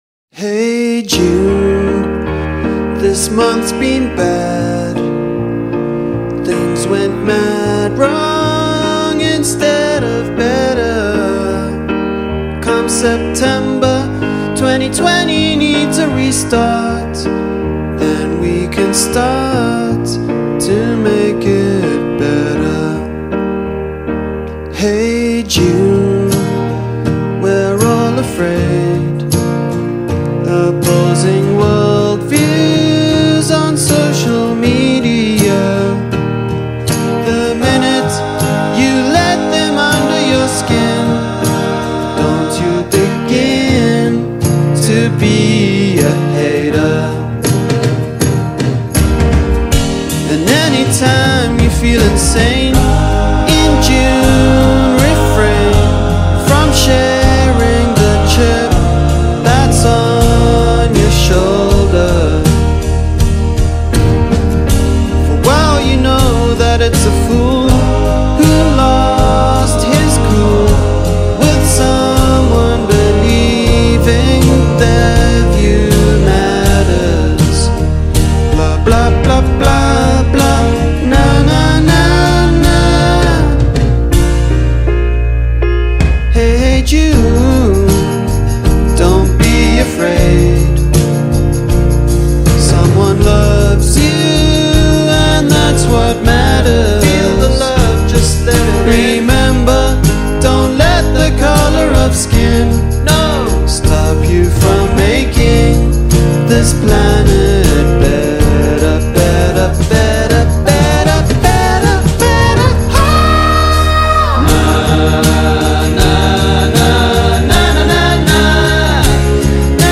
3) OPENING CORONAVIRUS PARODY SONG